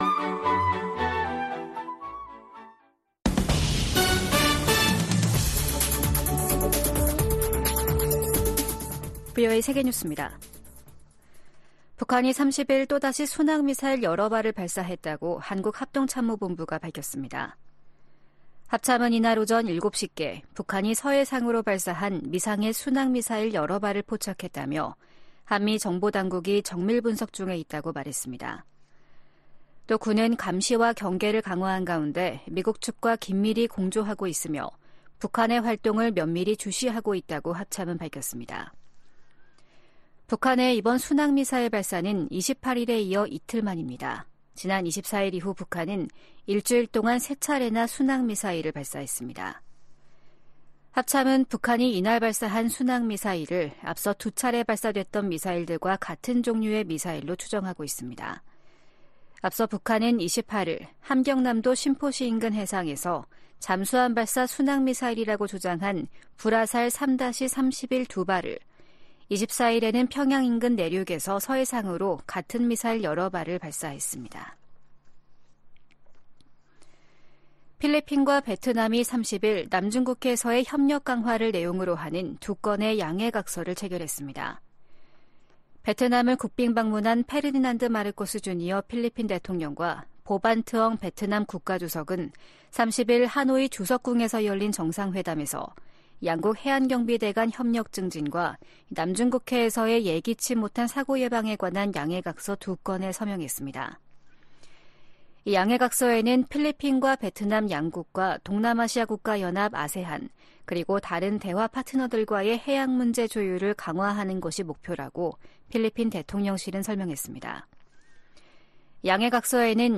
VOA 한국어 아침 뉴스 프로그램 '워싱턴 뉴스 광장' 2024년 1월 31일 방송입니다. 북한이 이틀 만에 서해상으로 순항미사일 여러 발을 발사했습니다. 미국 국방부가 북한의 순항미사일 발사와 관련해 미한일 3국 협력의 중요성을 강조했습니다.